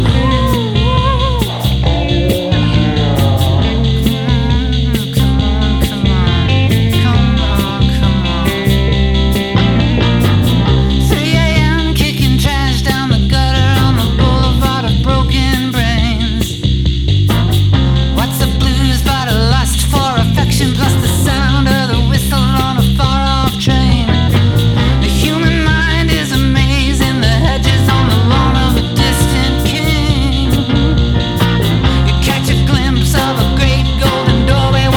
Indie Rock Alternative
Жанр: Рок / Альтернатива